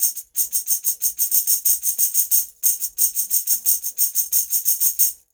90 TAMB4.wav